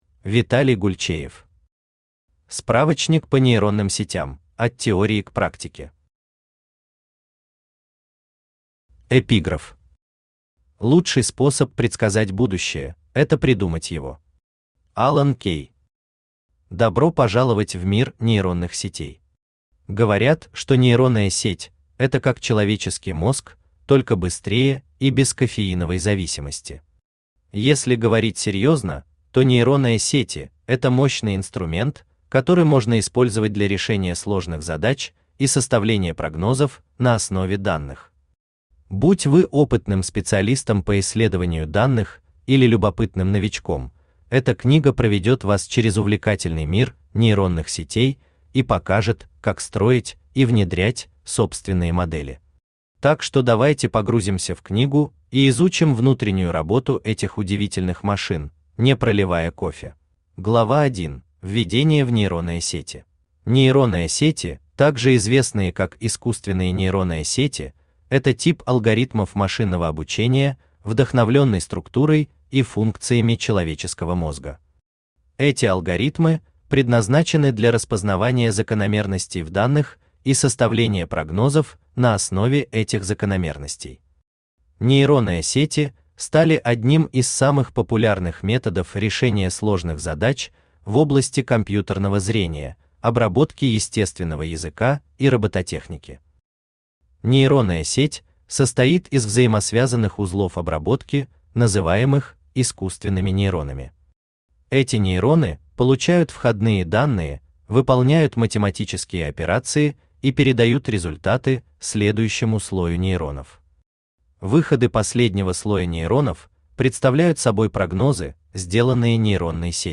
Аудиокнига Справочник по нейронным сетям: от теории к практике | Библиотека аудиокниг
Aудиокнига Справочник по нейронным сетям: от теории к практике Автор Виталий Александрович Гульчеев Читает аудиокнигу Авточтец ЛитРес.